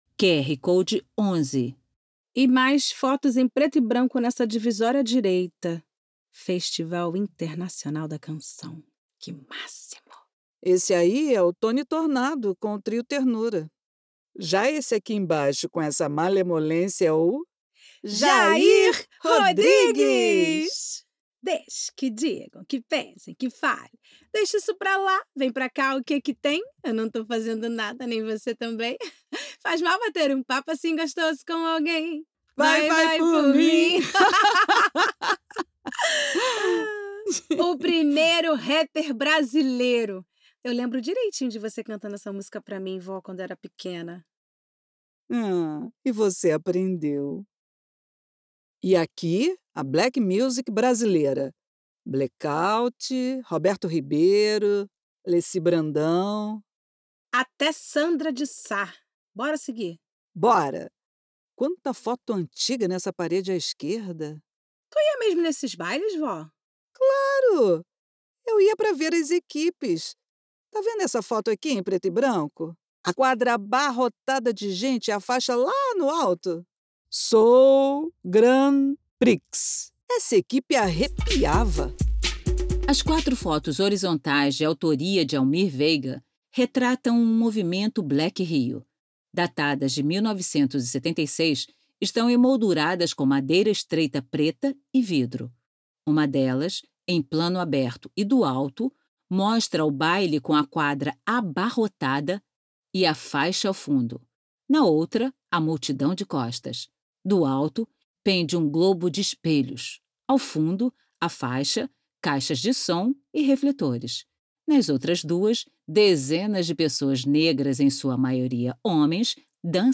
Audiodescrição